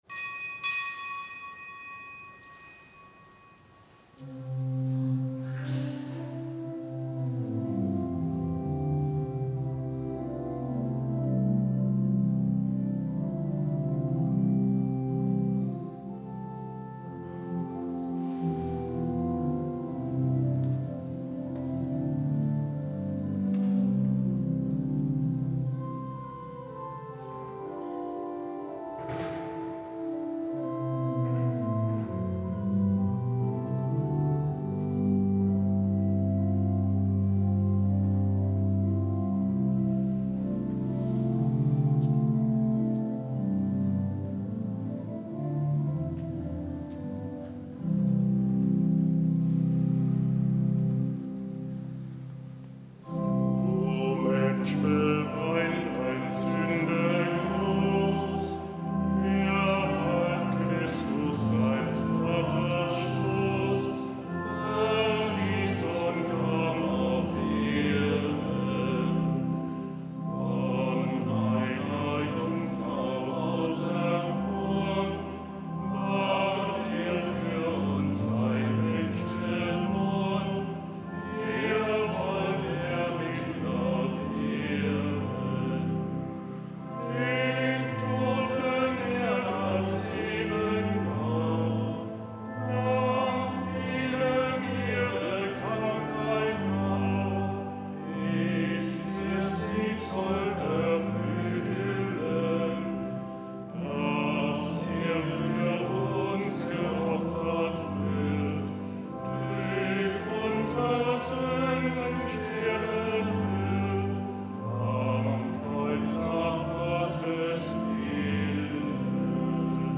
Kapitelsmesse aus dem Kölner Dom am Montag der fünften Fastenwoche.